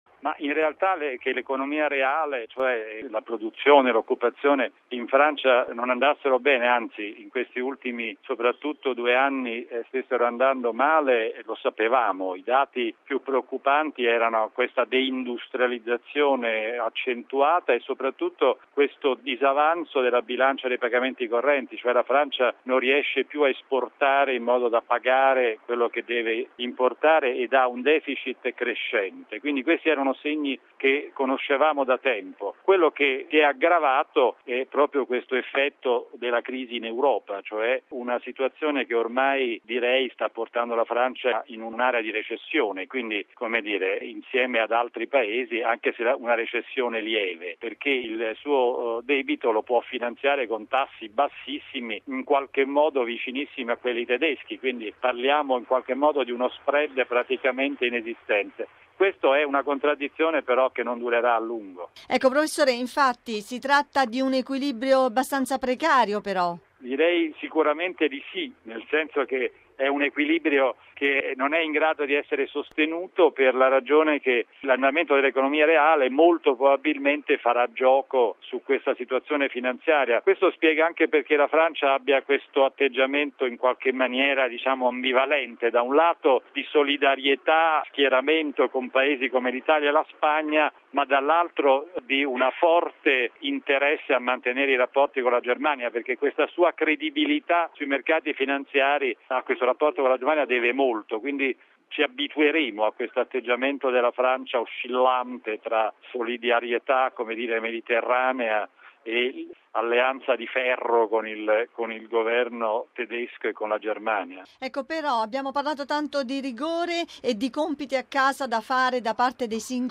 ha intervistato